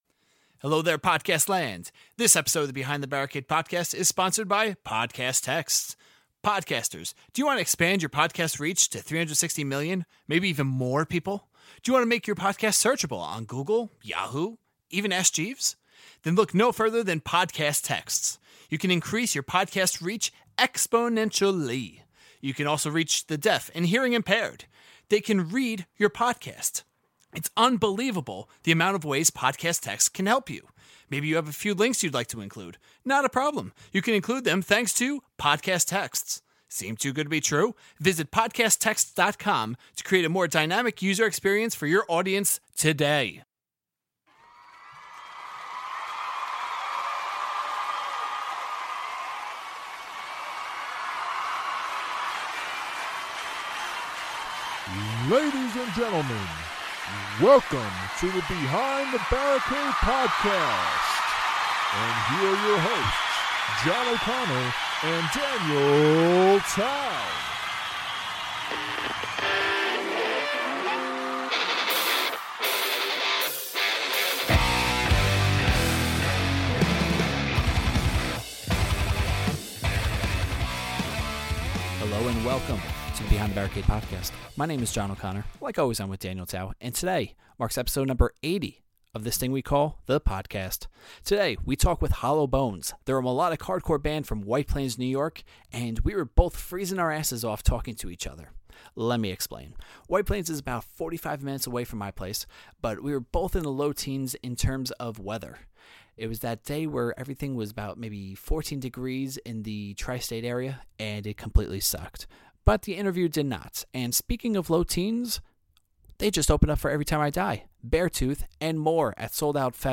Episode 80 of the podcast features White Plains, NY melodic and hardcore band Hollow Bones! We talk about playing Souled Out Fest with Beartooth/Every Time I Die, their interesting album cover for Lionheart, working on a new concept album and more!